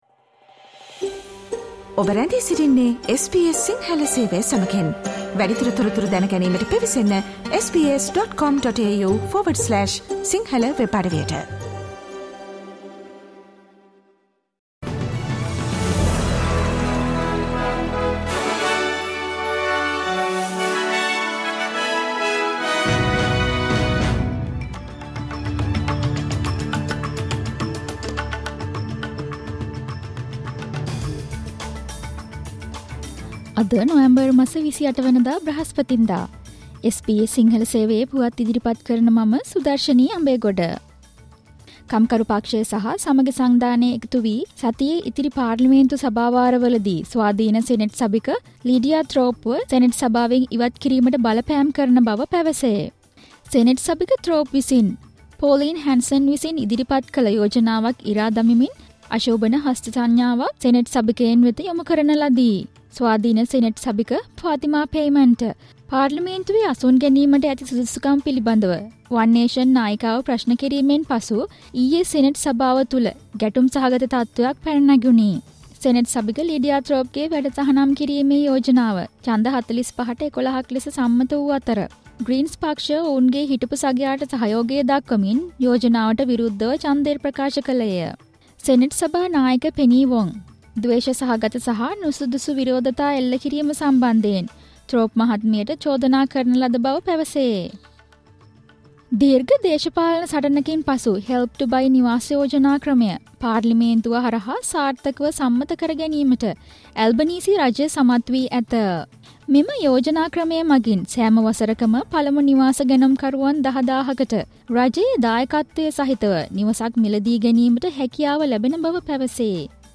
Australian news in Sinhala, foreign and sports news in brief - listen, SBS Sinhala radio news on Thurseday 28 November 2024